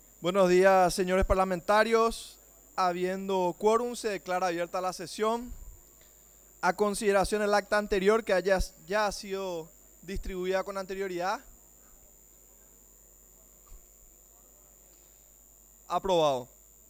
Sesión Ordinaria, 12 de julio de 2023